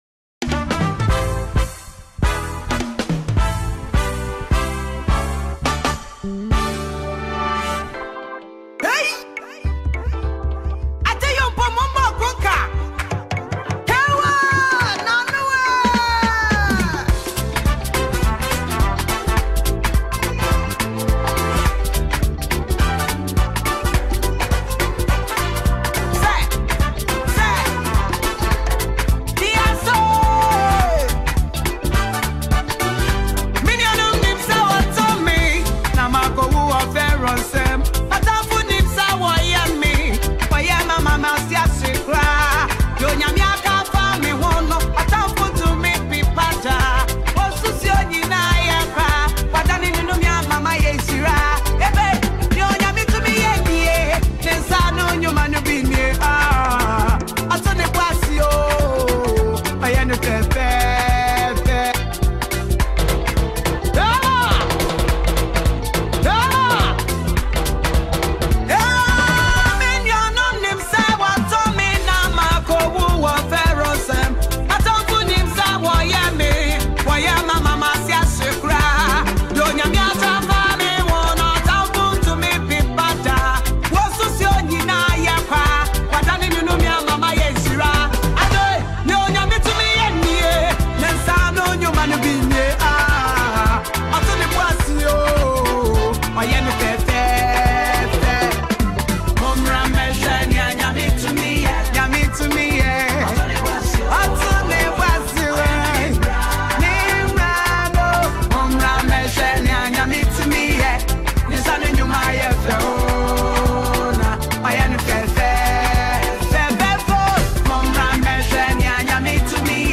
popular trending and beautiful Ghanaian gospel minister